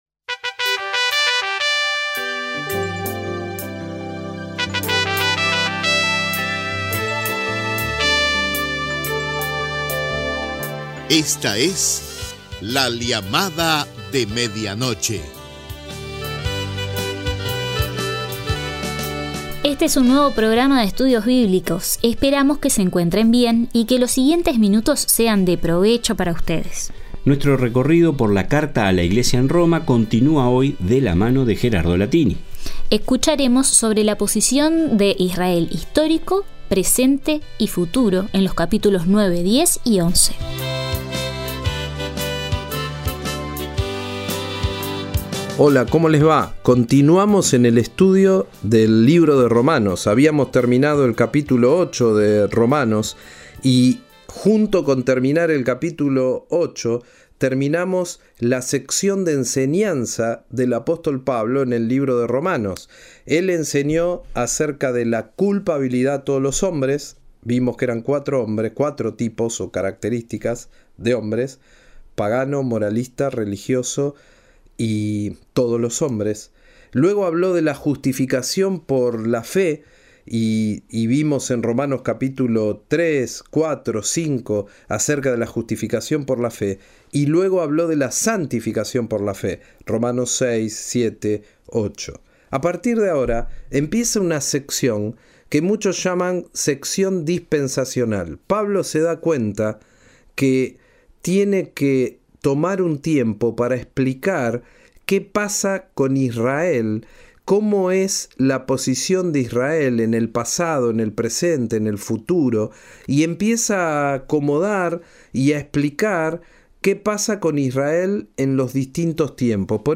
Próximamente transcripción de la entrevista